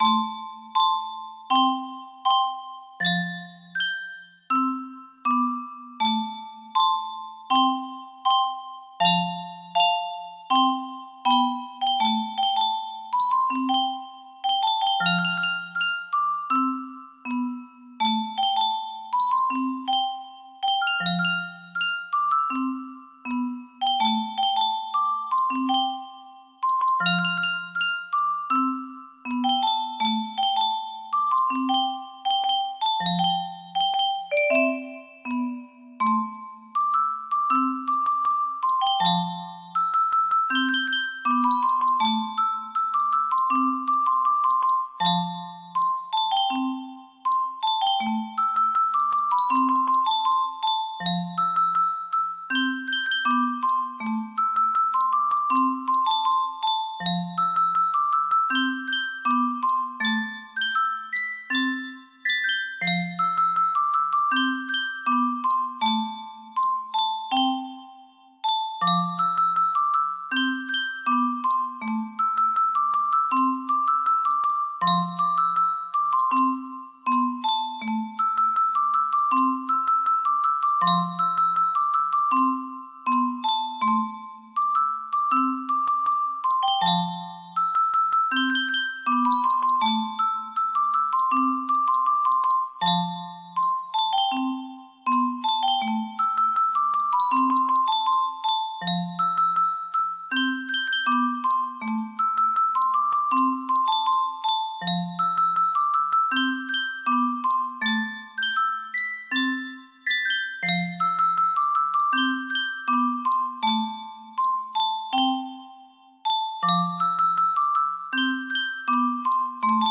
bar 1 music box melody